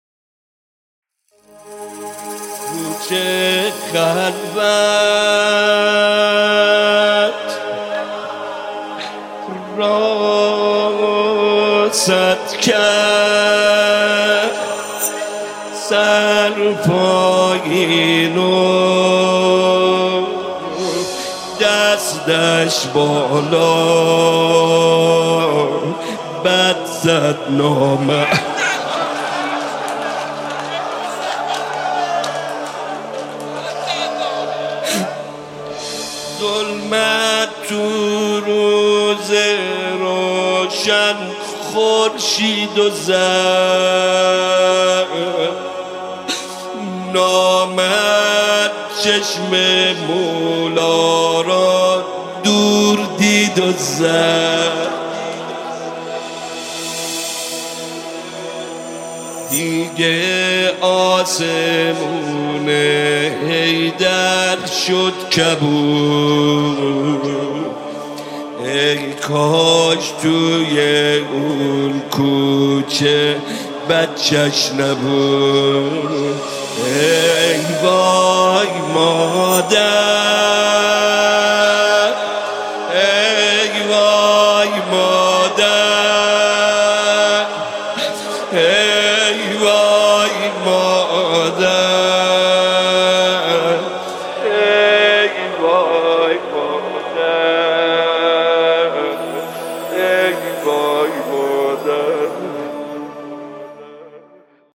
نماهنگ جدید فاطمیه